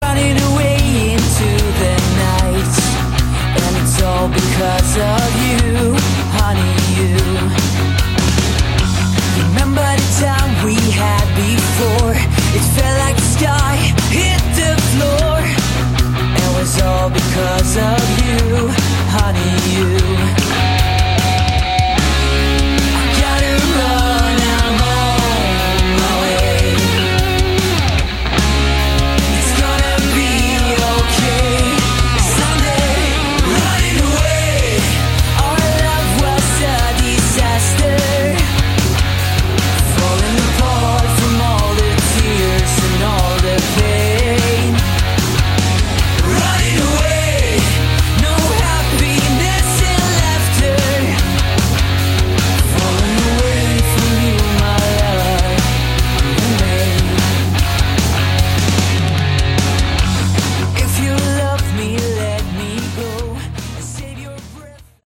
Category: Sleaze Glam / Hard Rock
drums
guitar, Lead vocals
guitar, backing vocals
Bass, backing vocals